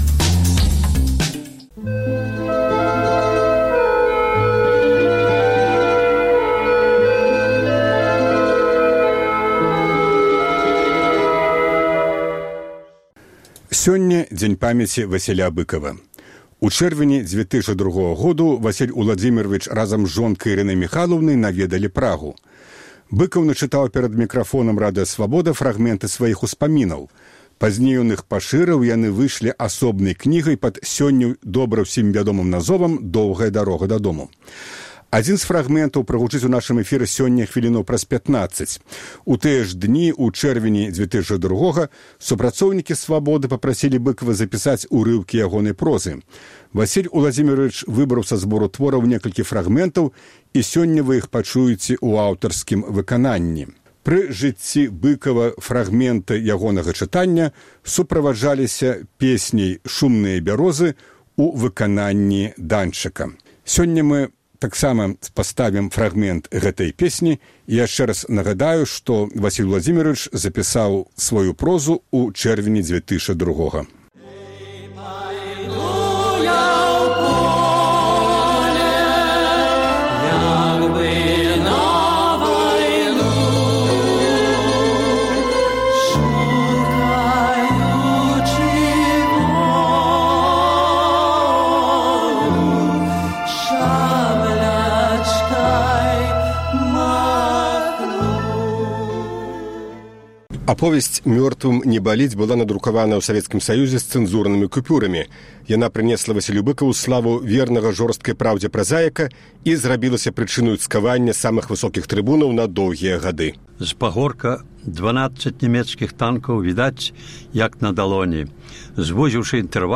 Быкаў чытае фрагмэнты сваіх твораў
У тыя ж дні у чэрвені 2002-га супрацоўнікі Свабоды папрасілі Быкава запісаць урыўкі ягонай прозы. Васіль Уладзімеравіч выбраў са збору твораў некалькі фрагмэнтаў, і сёньня вы можаце пачуць іх у аўтарскім выкананьні.